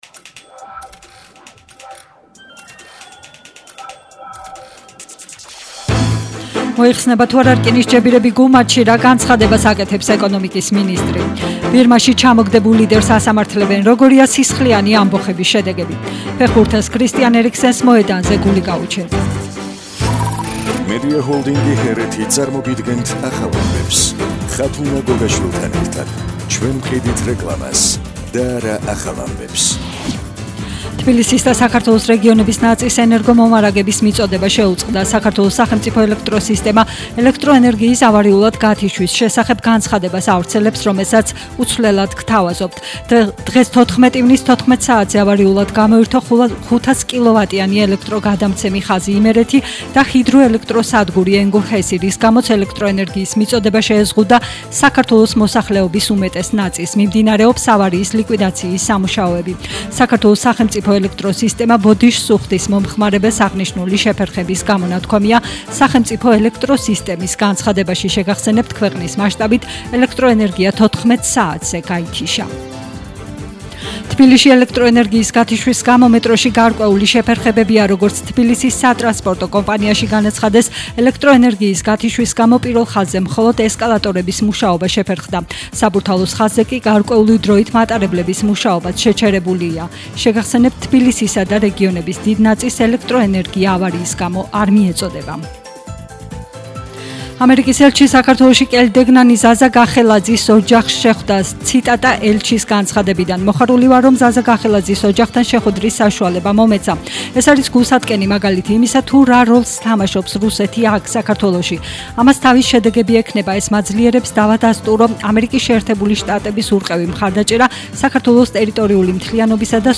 ახალი ამბები 15:00 საათზე –14/06/21 - HeretiFM